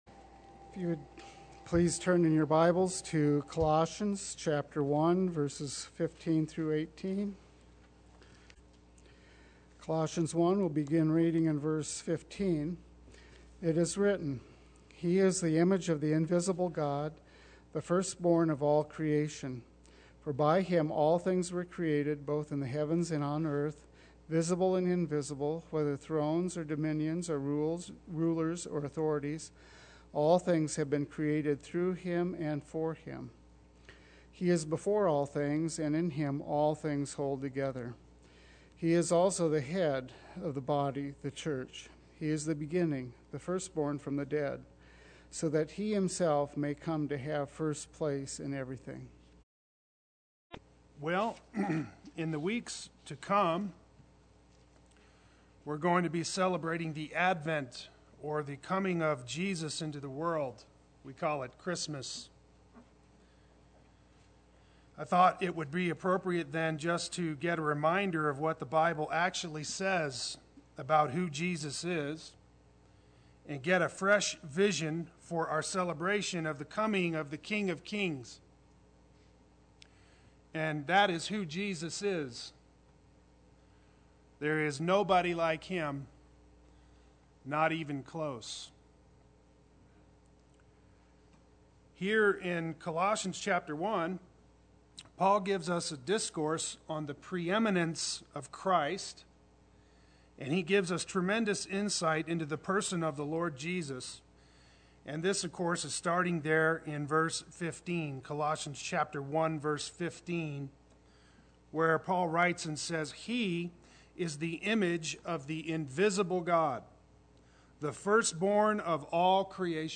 Play Sermon Get HCF Teaching Automatically.
He Will Have First Place in Everything Sunday Worship